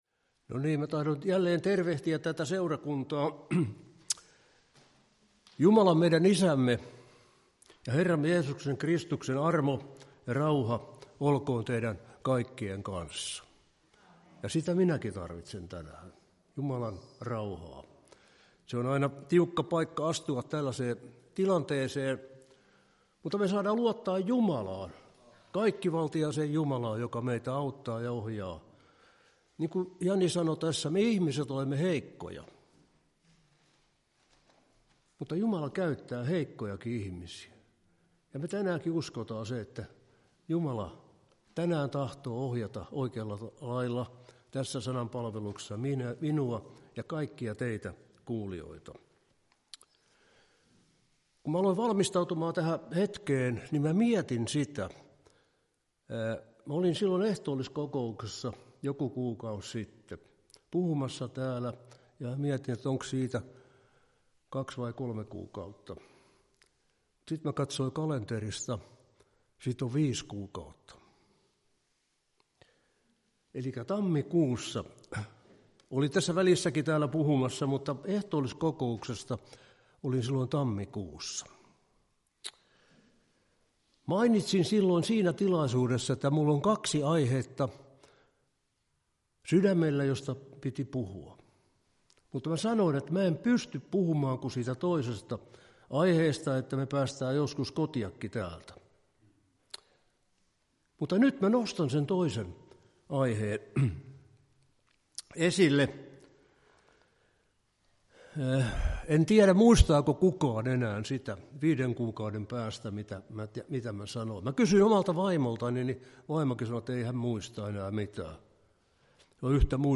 Ehtoolliskokous